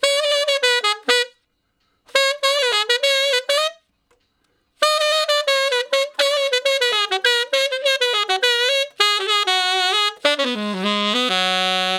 068 Ten Sax Straight (Ab) 16.wav